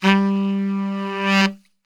G 1 SAXSWL.wav